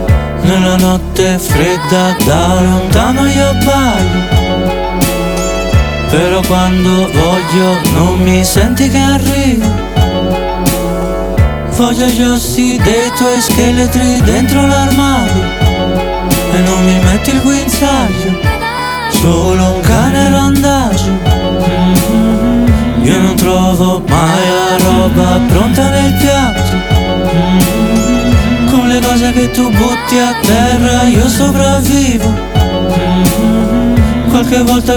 Rap Hip-Hop Rap
Жанр: Хип-Хоп / Рэп